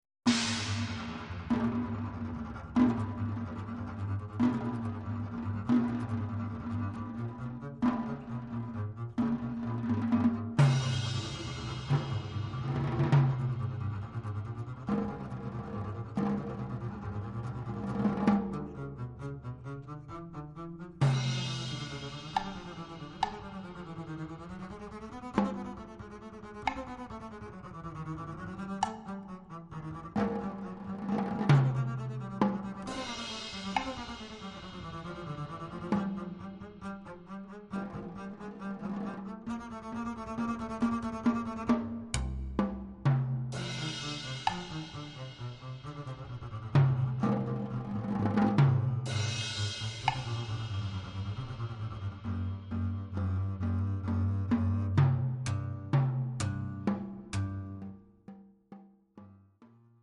(a Pas de Duex for Double-Bass and Percussion)